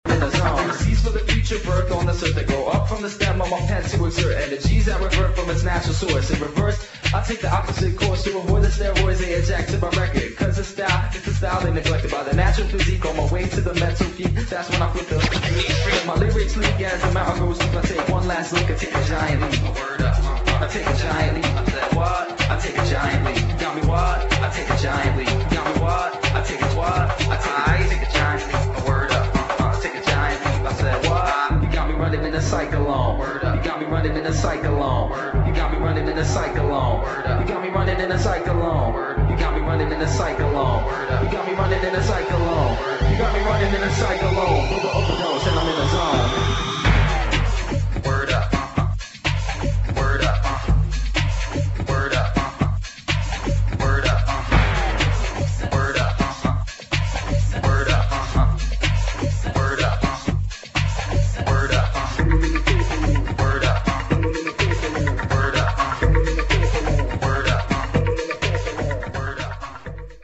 [ ROCK / BIG BEAT / BREAK BEAT ]